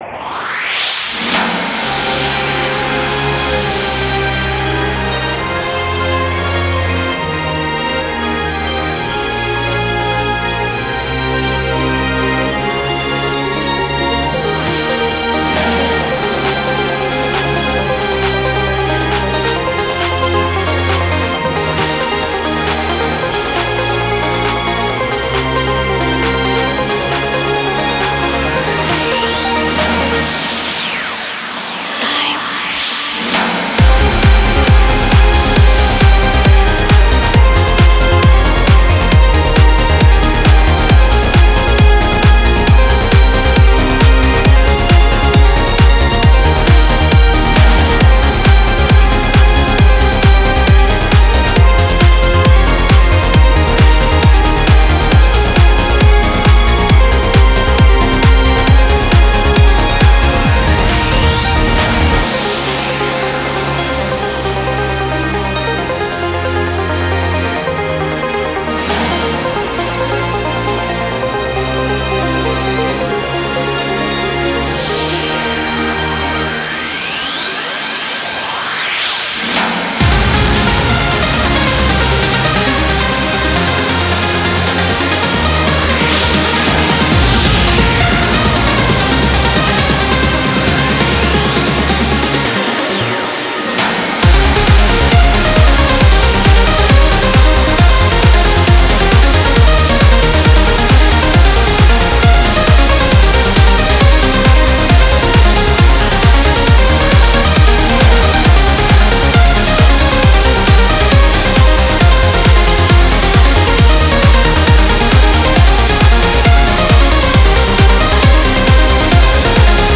BPM135
Comments[PROGRESSIVE TRANCE]